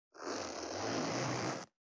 431洋服関係音,ジー,バリバリ,カチャ,ガチャ,シュッ,パチン,ギィ,カリ,
ジッパー効果音洋服関係
ジッパー